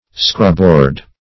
Scrubboard \Scrub"board`\ (skr[u^]b"b[=o]rd), n.